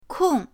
kong4.mp3